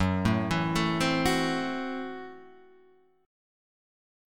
F#m7b5 chord {2 0 2 2 1 2} chord